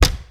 22. 22. Percussive FX 21 ZG